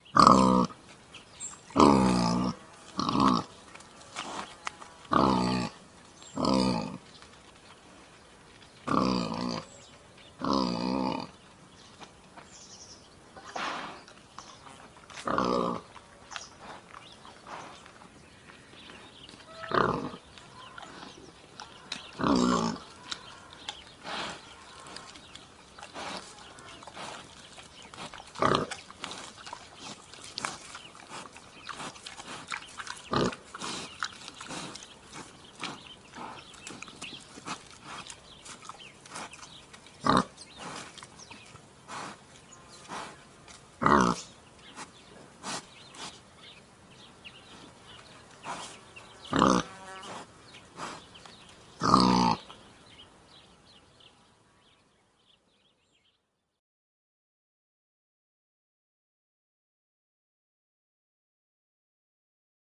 Животное в естественной среде обитания